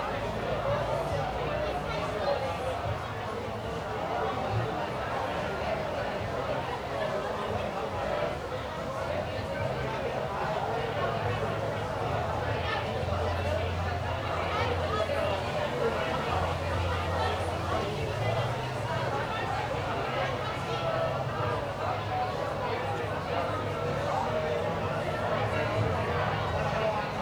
background_crowd_people_chatter_loop_02.wav